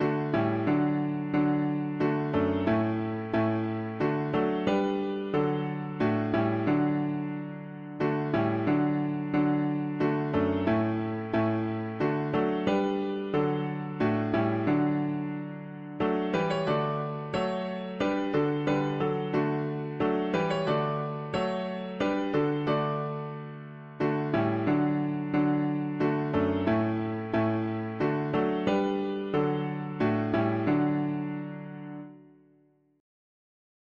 Teach me some … english christian 4part
American folk melody
Key: D major